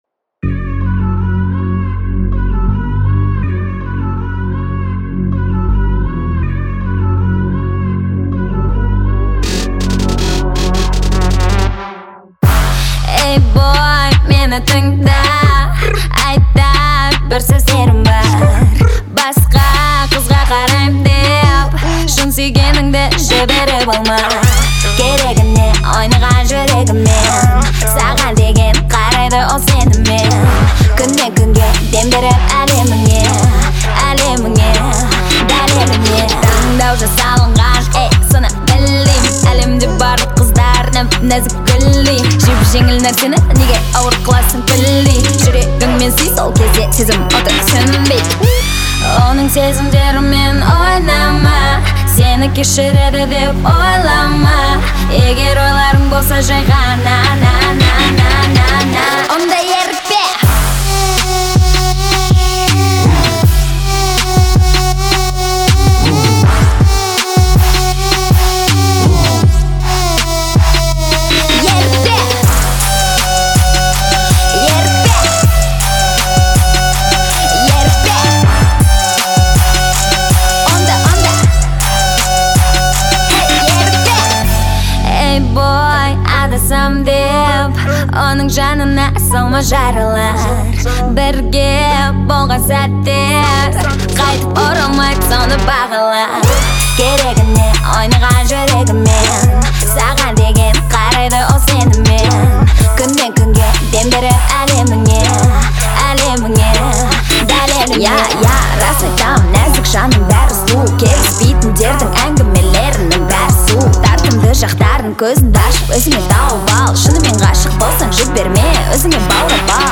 выразительный вокал и запоминающийся мелодичный хук